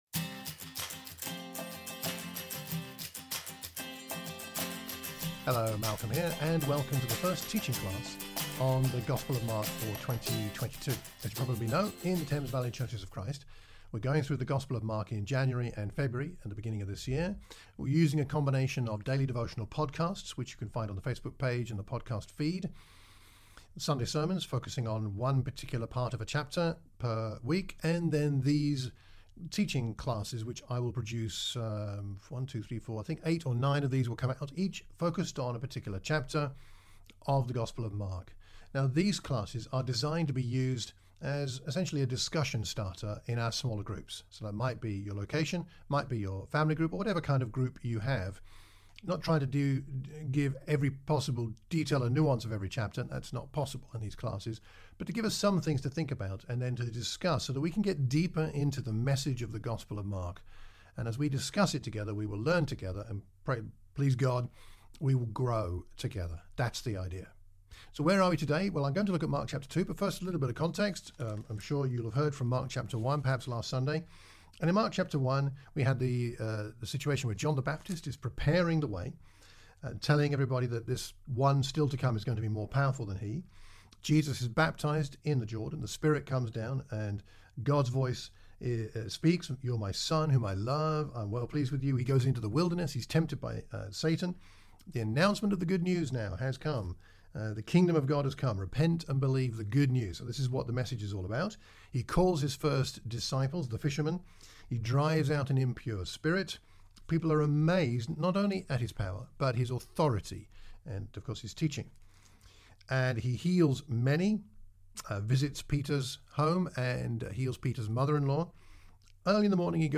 A teaching class for the Thames Valley churches of Christ.